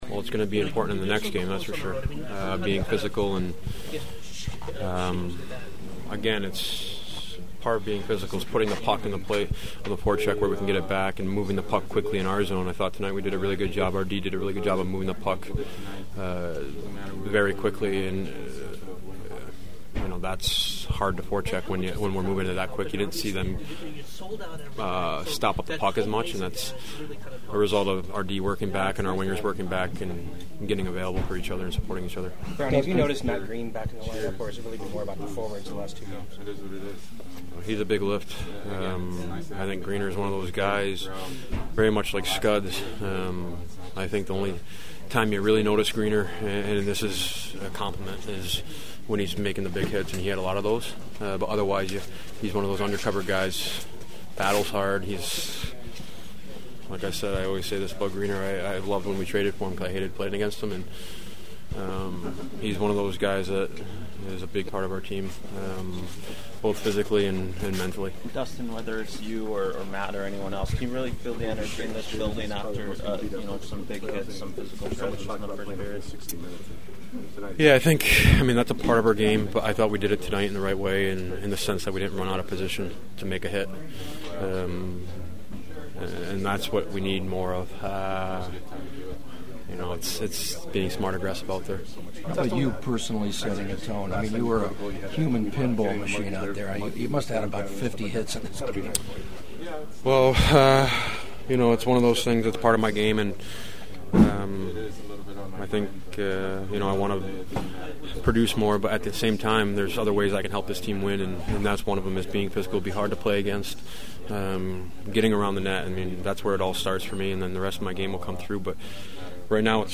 The sounds of the postgame are ahead from a confident Kings locker room: